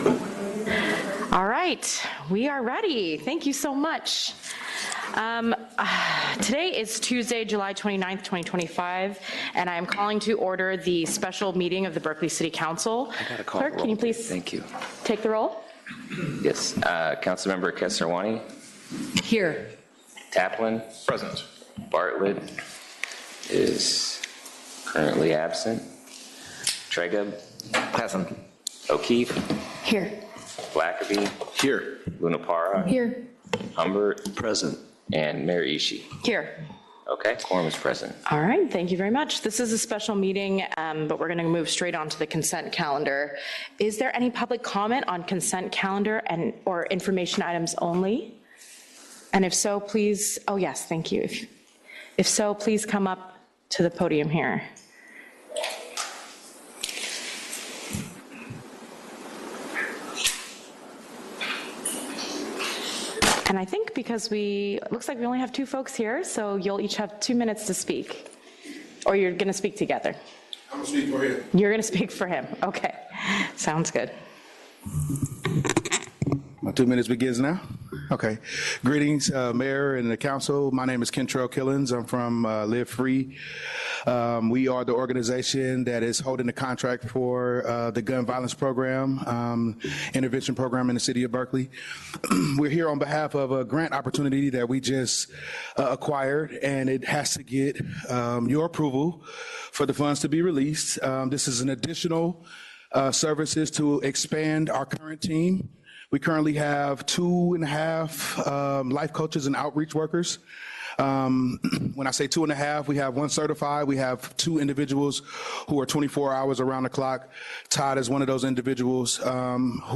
This meeting will be conducted in a hybrid model with both in-person attendance and virtual participation.